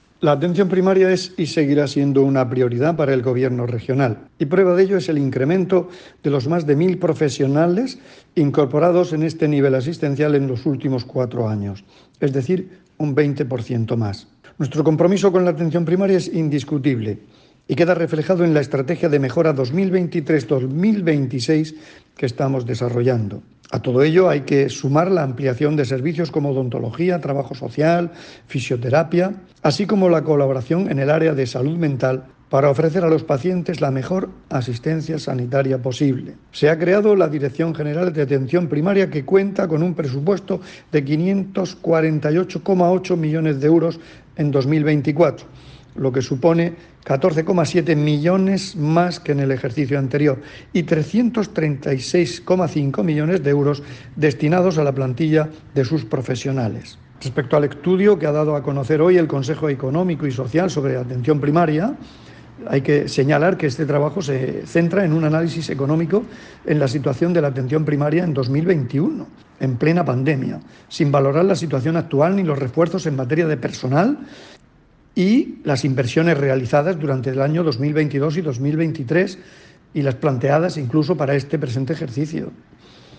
Declaraciones del consejero de salud, Juan José Pedreño, sobre la actual situación de la Atención Primaria en la Región.